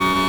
ezsentry_turn.wav